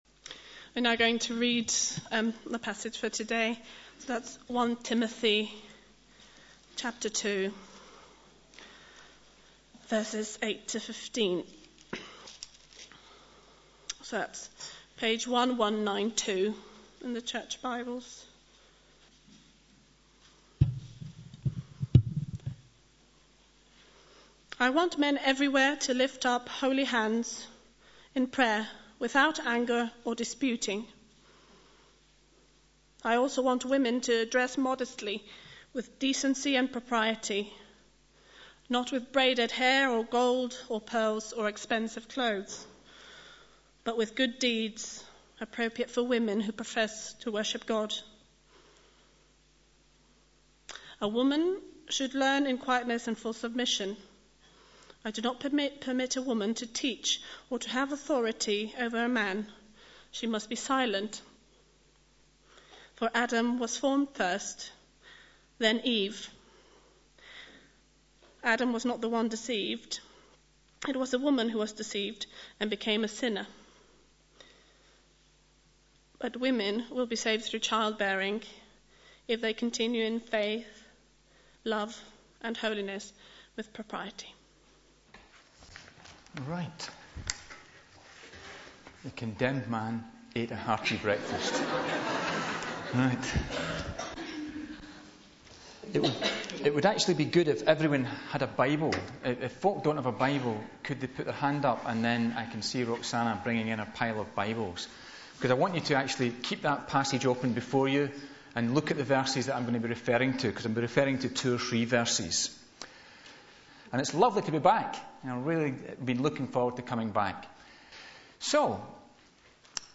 Sunday Service
Passage: 1 Timothy 2.8-15; 5.11-25 Series: The Pastoral Epistles Theme: Sermon